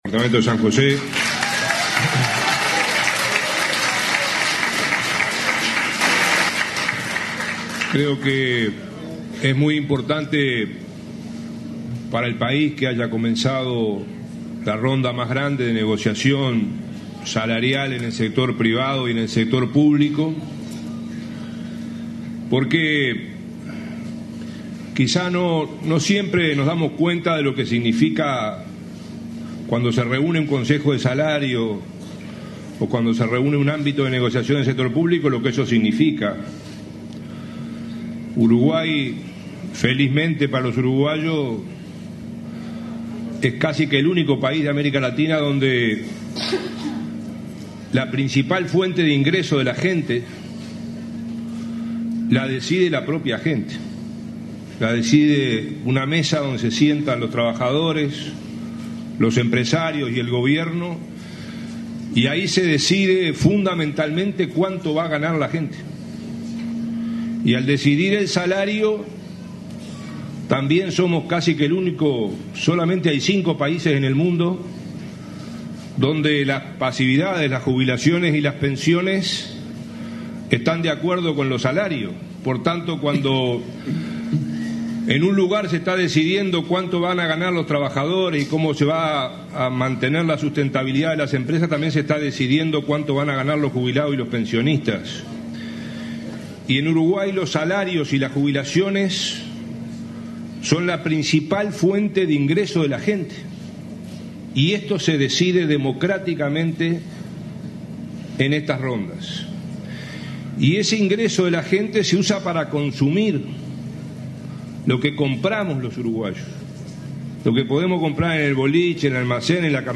“Los uruguayos debemos festejar que tenemos la ronda más grande de negociación colectiva con participación de empresarios y trabajadores”, sostuvo el ministro de Trabajo, Ernesto Murro, durante el Consejo de Ministros abierto de San José. Dijo que Uruguay es el único país de América Latina donde los salarios son decididos democráticamente y en conjunto por ambos actores y el gobierno en la negociación colectiva.